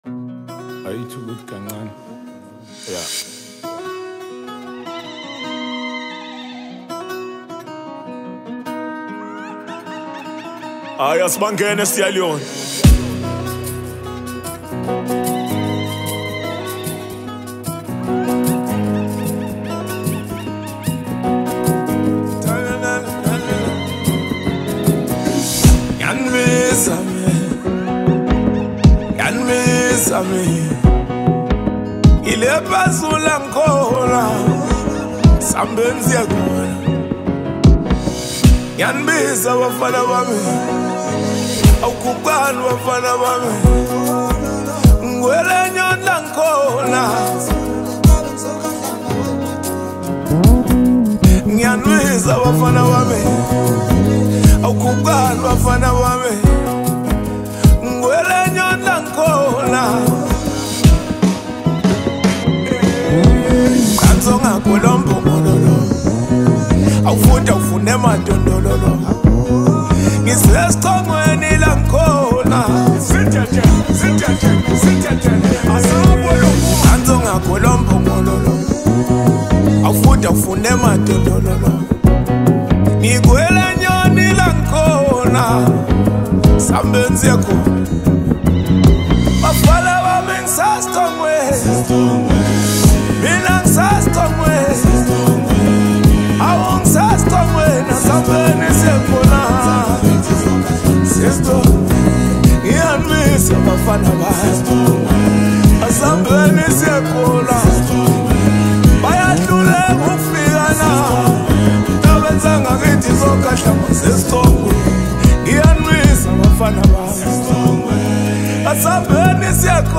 It’s part of his latest Afro-Pop album